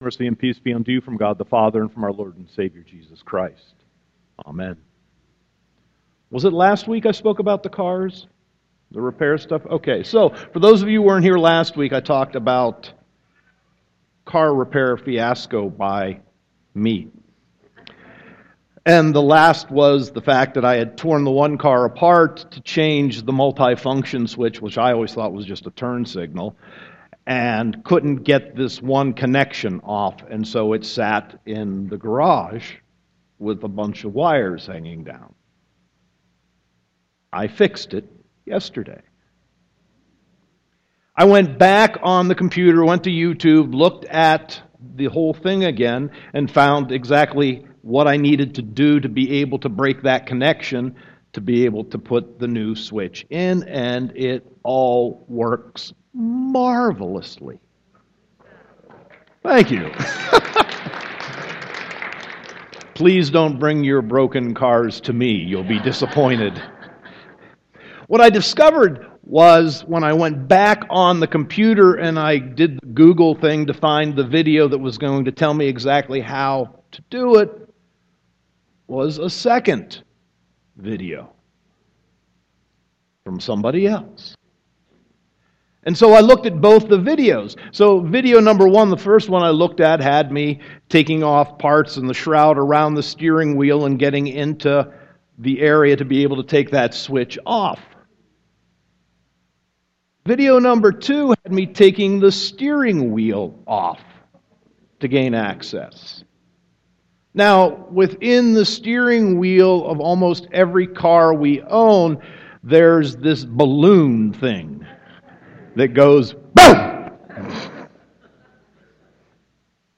Sermon 5.8.2016